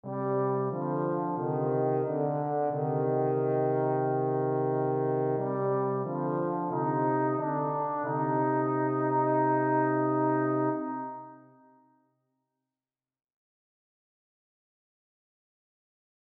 For example, this is the sample score you provided but with Miroslav Philharmonik instruments and the same Notion 3 Reverb setting . . .
FD-Octave-Miroslav-Philharmonik-Reverb.mp3